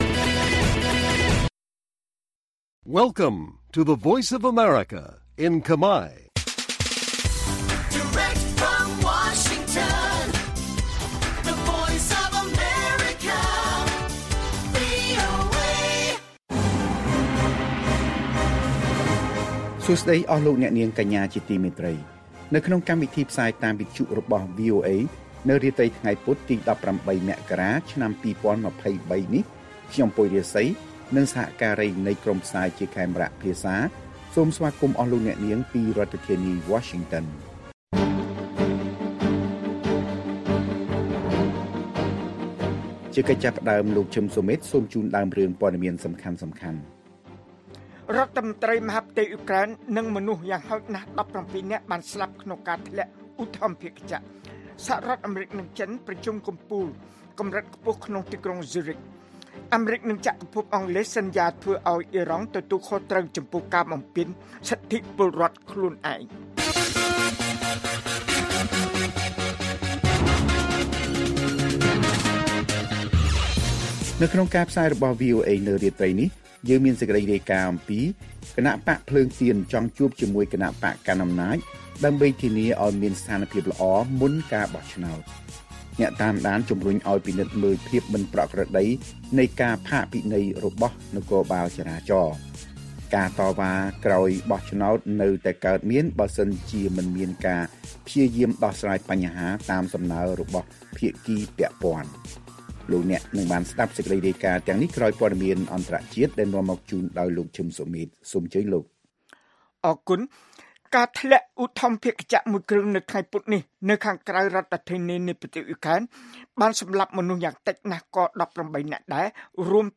ព័ត៌មានពេលរាត្រី ១៨ មករា៖ រដ្ឋមន្ត្រីមហាផ្ទៃអ៊ុយក្រែន និងមនុស្សយ៉ាងហោចណាស់១៧នាក់បានស្លាប់ក្នុងការធ្លាក់ឧទ្ធម្ភាគចក្រ